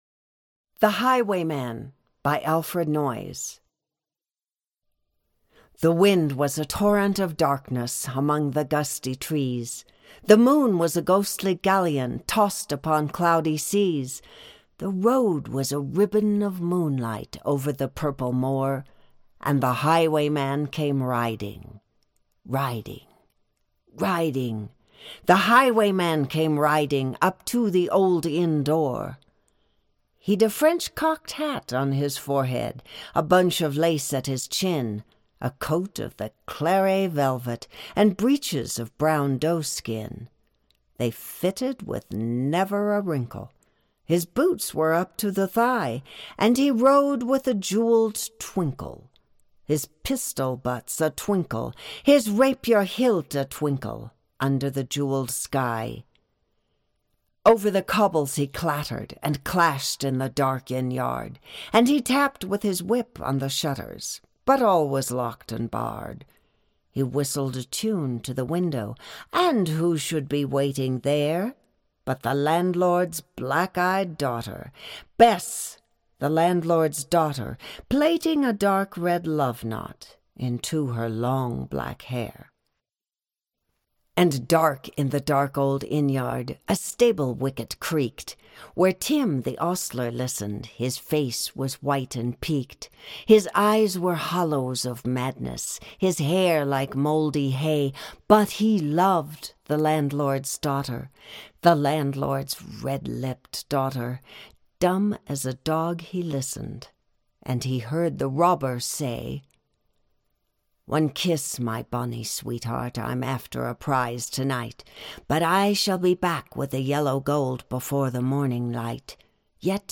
Free - Audio-Book - The Highwayman
Project Gutenberg Session Notes ... read more Download Here TheHighwayman.mp3 Category: Mature , Poetic , Fiction , Eerie